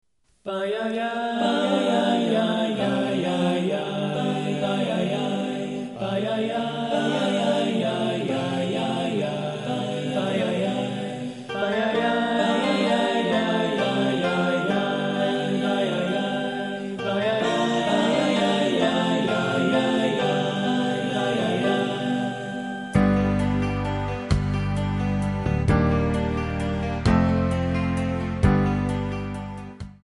Backing track Karaoke
Pop, Musical/Film/TV, 1990s